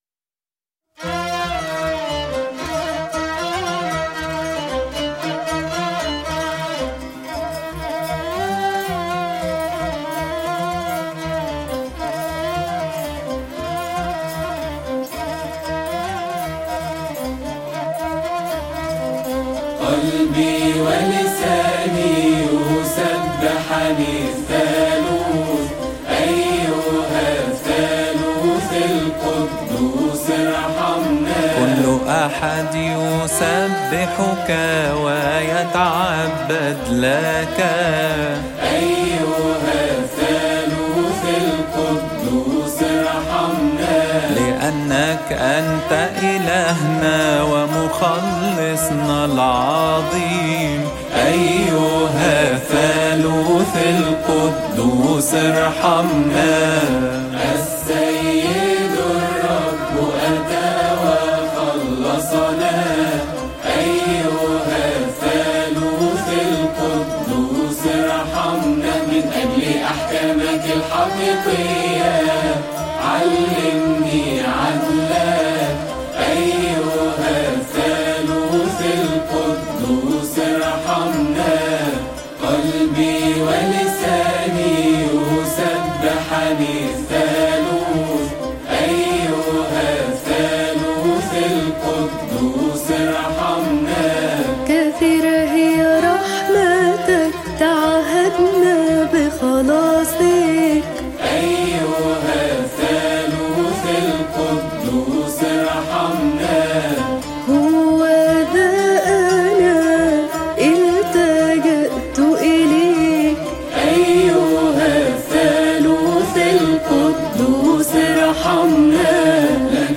ابصالية آدام رابعة (قلبي و لساني) عربي، لفريق يوتيرن.
المصدر: فريق يوتيرن
قلبي-ولساني-فريق-يوتيرن-حفل-لحن-الكريسماس-U-Turn-Team-SoundLoadMate.com_.mp3